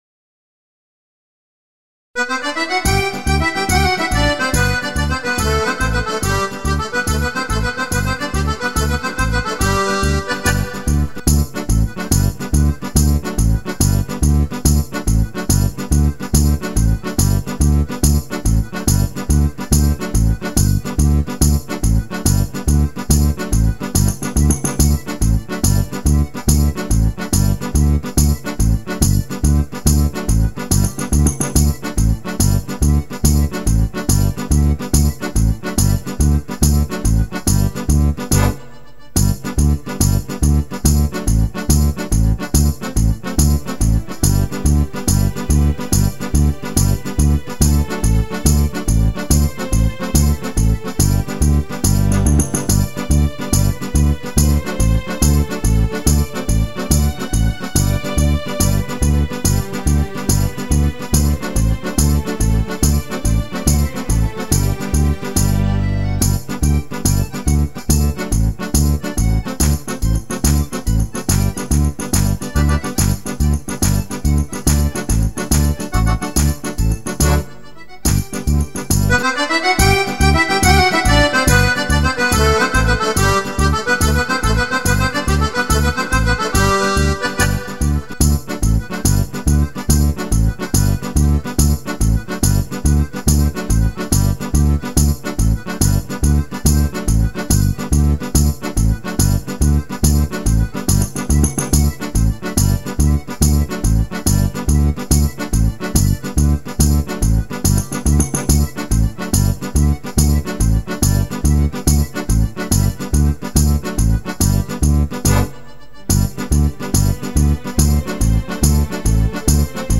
(midi KETRON SD5)